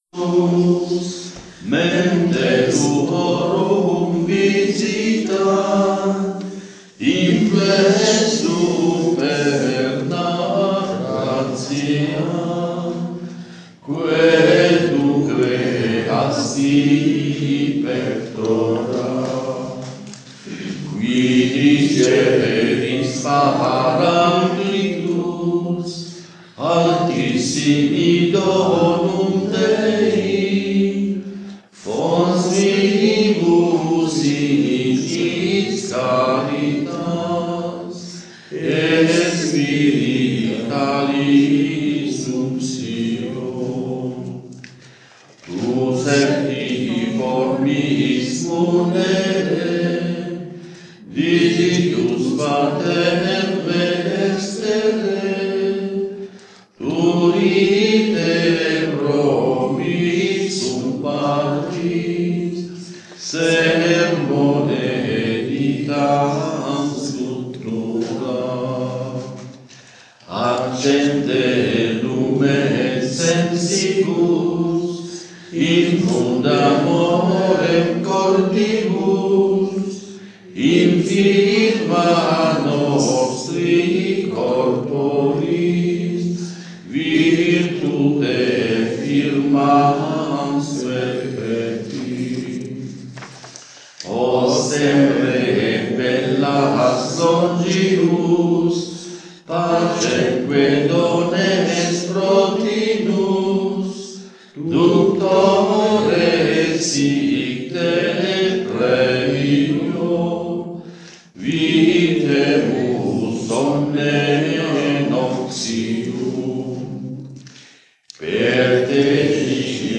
Il canto Gregoriano è liturgico, solitamente interpretato da un coro o da un solista chiamato appunto cantore (cantor) o spesso dallo stesso celebrante [...]
Il Canto Gregoriano durante le serate di Fraternità
i Terziari Francescani cantano “Veni Creator Spiritus”.
CANTOGREGORIANO-DA-STUDIARE.mp3